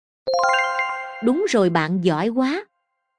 Tiếng chuông Đúng Rồi Bạn Giỏi Quá (Nữ, Miền Nam)
Thể loại: Hiệu ứng âm thanh
Description: Tiếng chuông khen ngợi "Đúng Rồi Bạn Giỏi Quá" với giọng nữ miền Nam ấm áp, thân thiện, phù hợp cho giáo viên mầm non, tiểu học tạo động lực khích lệ học sinh.
tieng-chuong-dung-roi-ban-gioi-qua-nu-mien-nam-www_tiengdong_com.mp3